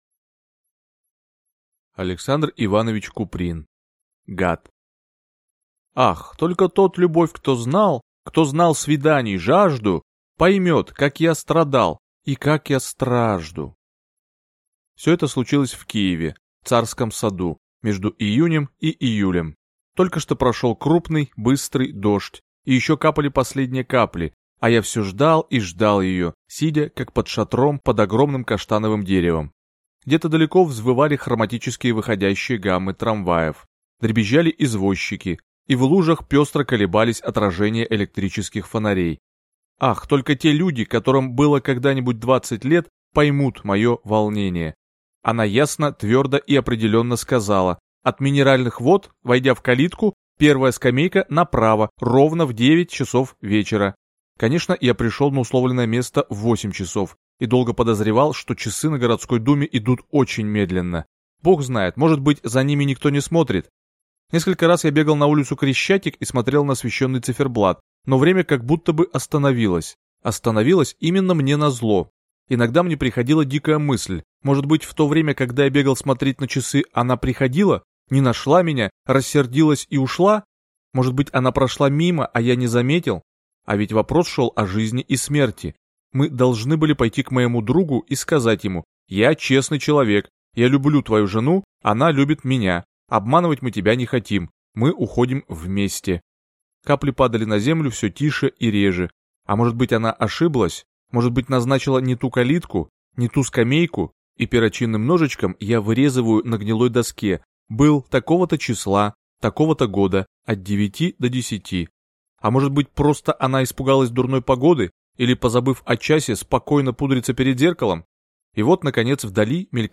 Аудиокнига Гад | Библиотека аудиокниг